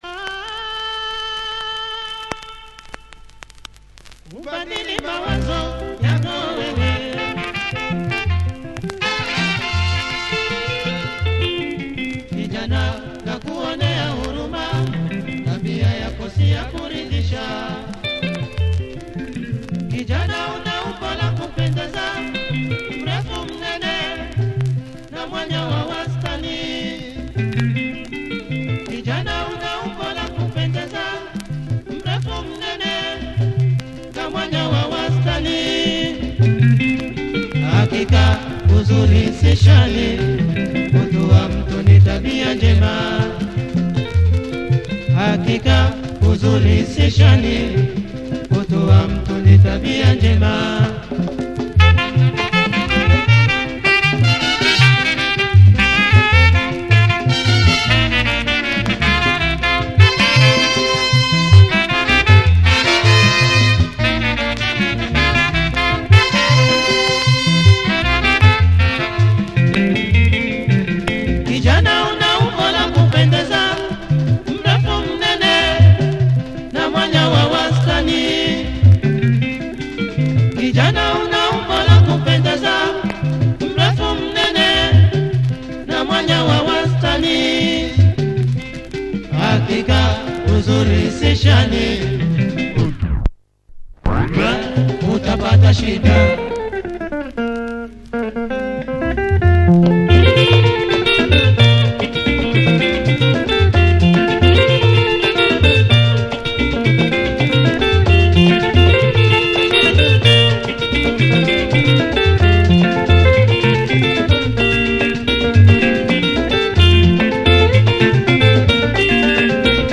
Great Tanzanian rumba!